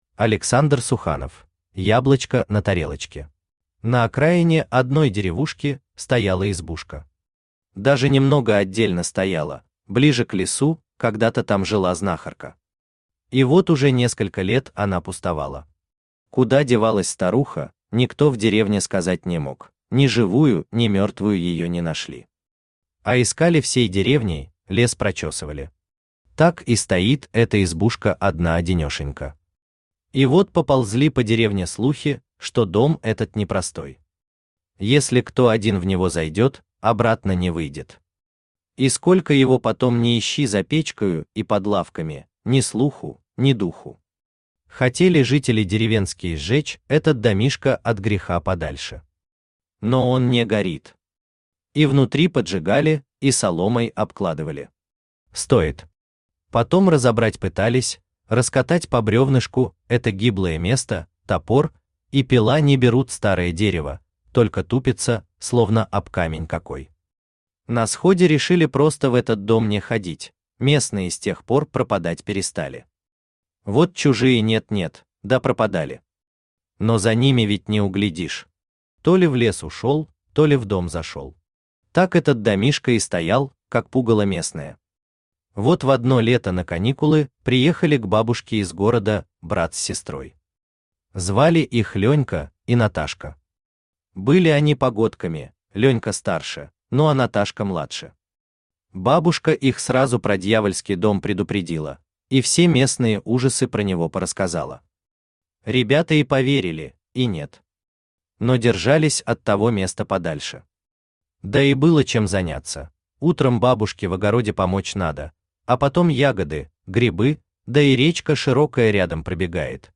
Аудиокнига Яблочко на тарелочке | Библиотека аудиокниг
Aудиокнига Яблочко на тарелочке Автор Александр Суханов Читает аудиокнигу Авточтец ЛитРес.